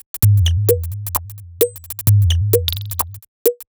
Sonic 130bpm.wav